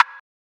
TAX - Woody Perc.wav